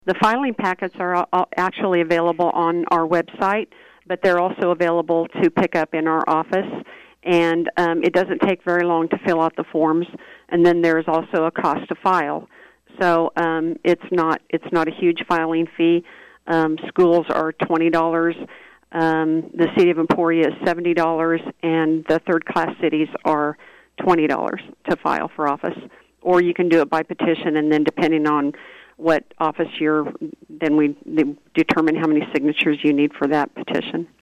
Lyon County Clerk Tammy Vopat recently joined KVOE’s Morning Show to discuss the upcoming candidate filing and withdrawal deadline. Vopat reminds hopeful candidates they have until noon on Jun. 1 to file their paperwork with the Lyon County Election Office.
vopat-filing.mp3